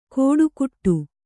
♪ kōḍu kuṭṭu